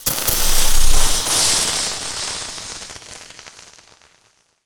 elec_lightning_magic_spell_08.wav